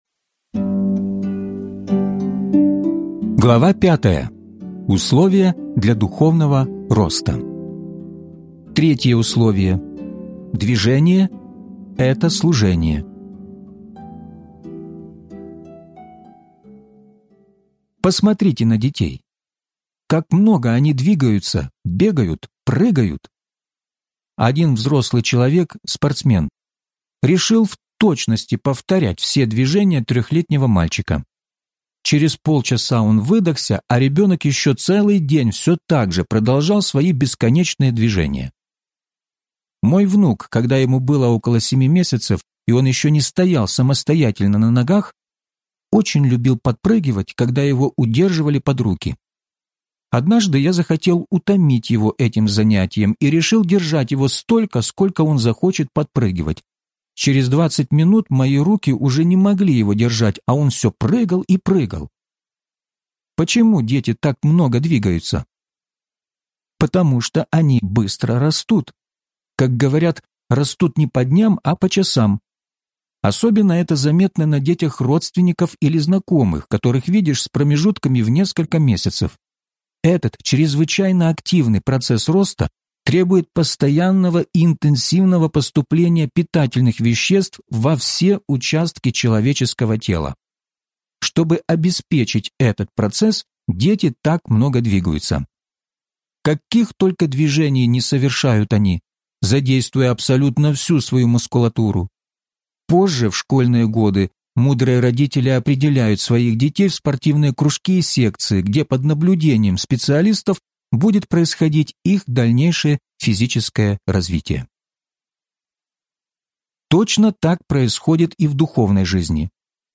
Возрастайте! (аудиокнига)